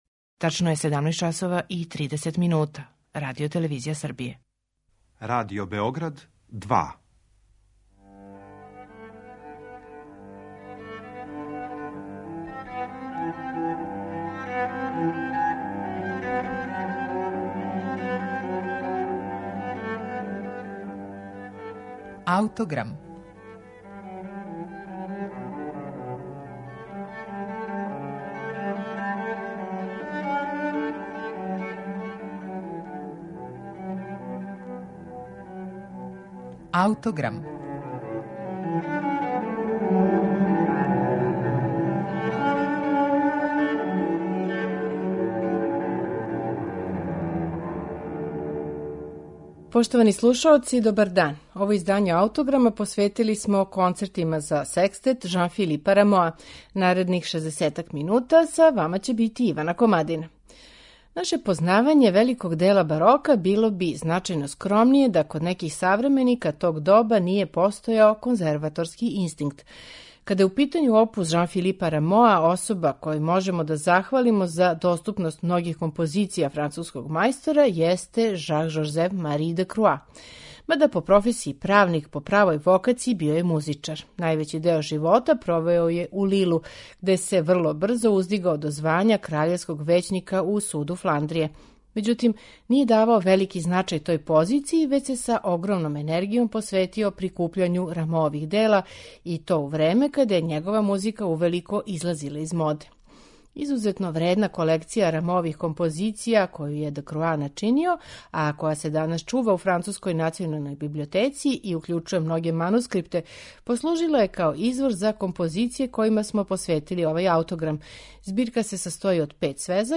Изузетно вредна колекција Рамоових дела коју је Дкроа начинио данас се чува у француској Националној библиотеци и послужила је као извор композиција којима смо посветили овај АУТОГРАМ: збирке Концерата за секстет, у којима су сабране и оркестриране композиције из чувене Рамоове збирке Комада за чембало. Четири концерта за секстет чућете у интерпретацији ансамбла Les Talens Lyriques, под управом Кристофа Русеа.